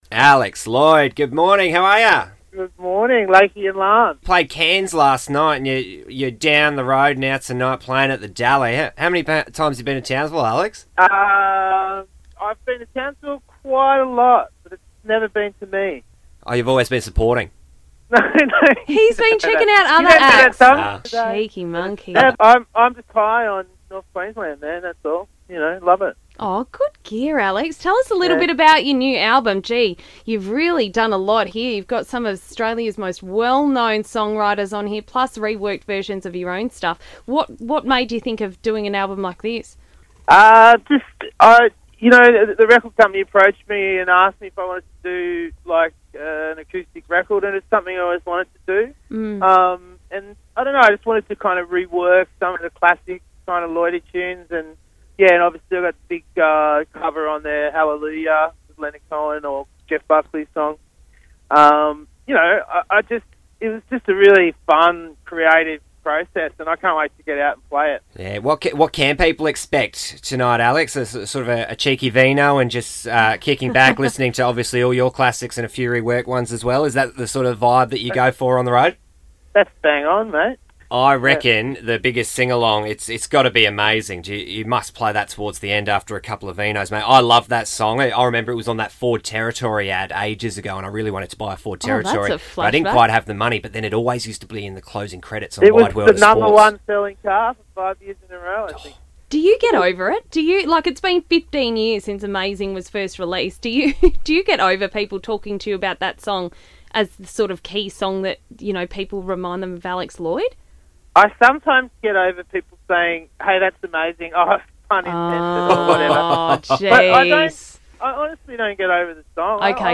chat to Alex Lloyd!